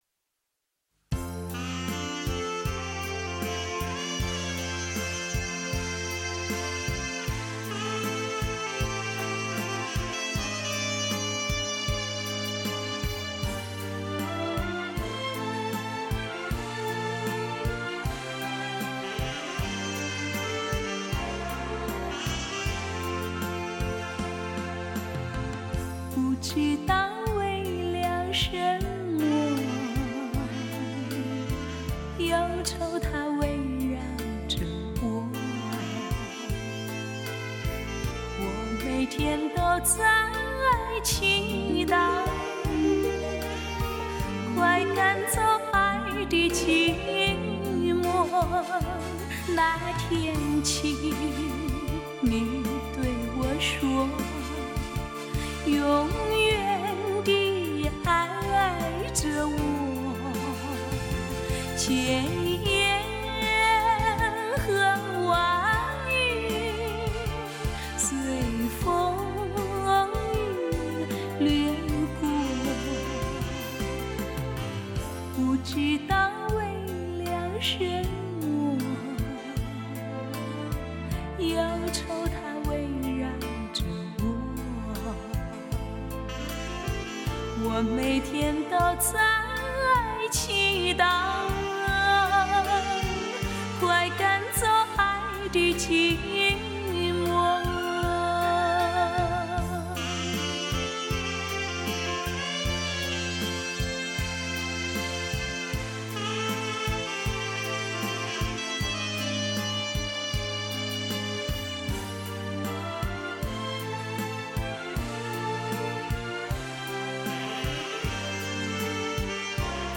24Bit/96Khz的多轨数码录音，保证了音色的清晰度和更宽阔的音域动态范围。
6个声道的数码输出，全面超越传统（2声道）的音效，您将完全融入音乐的包围中，系音效的极品之最